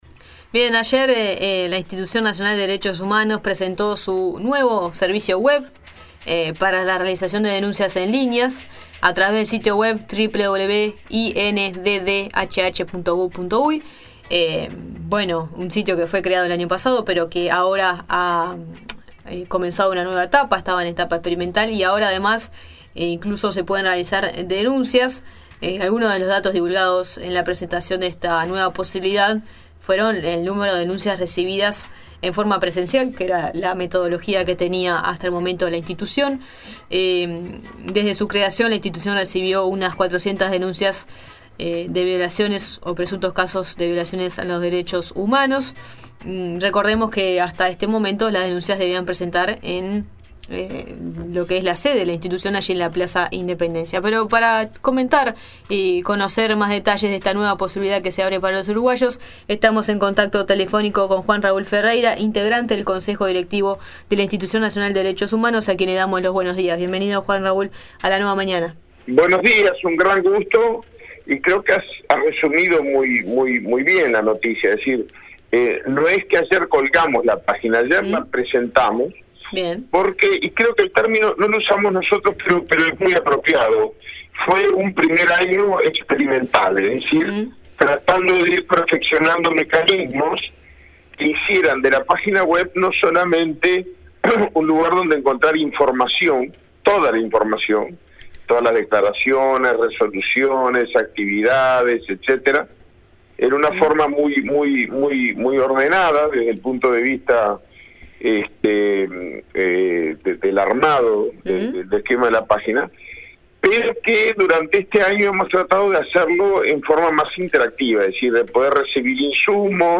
Sobre este recurso hablamos con Juan Raúl Ferrreira del Consejo Directivo de la INDDHH.